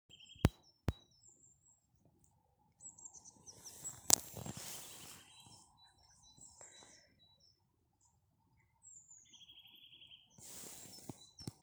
поползень, Sitta europaea